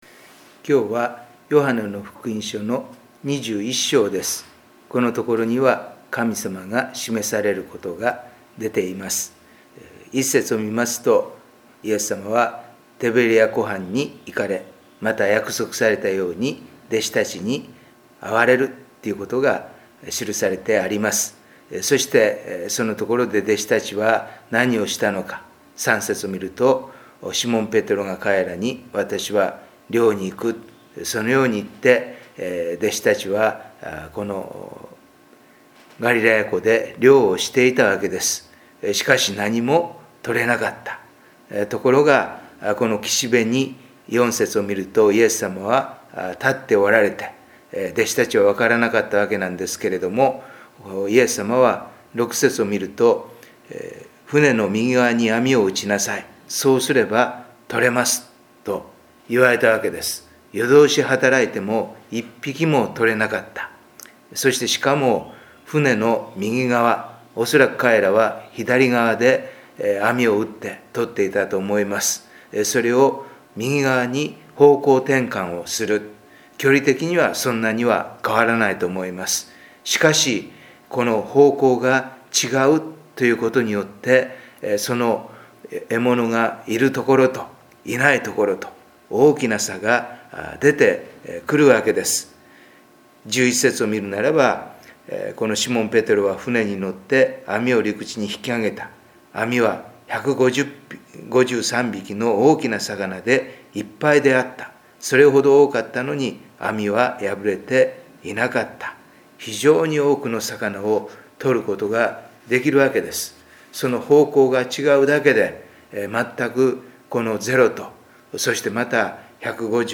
4月のデボーションメッセージ